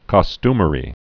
(kŏ-stmə-rē, -sty-)